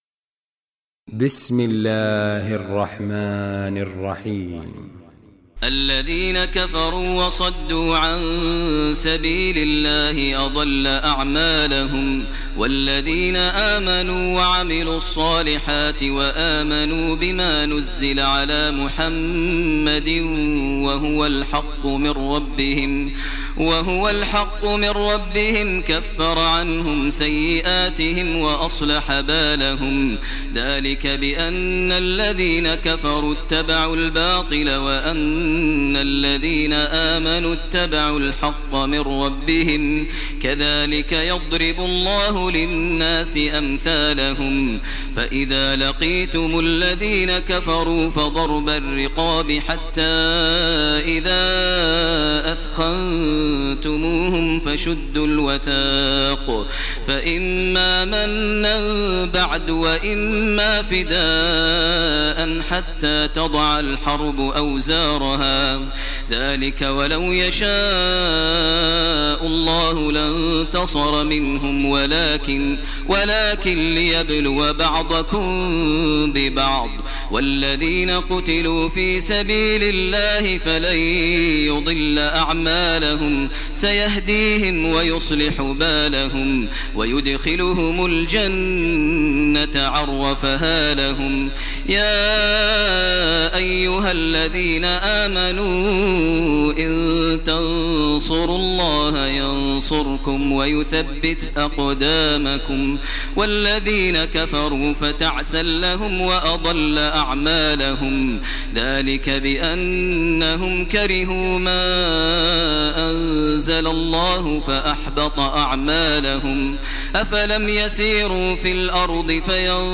Surah Muhammad Recitation by Maher al Mueaqly
Surah Muhammad, listen online mp3 tilawat / recitation in the voice of Sheikh Maher al Mueaqly.